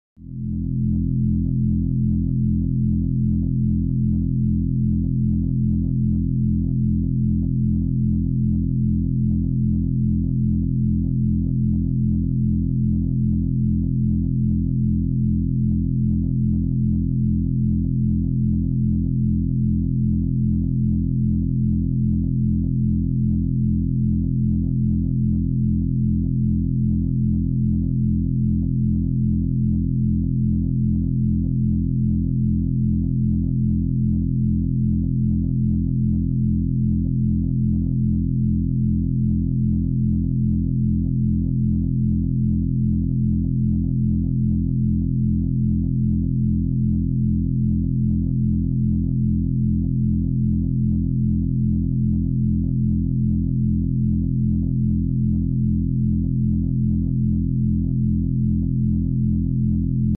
Binaural Beats BRAIN POWER Boost sound effects free download